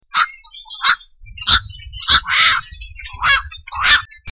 Bihoreau gris
Nicticorax nicticorax
bihoreau.mp3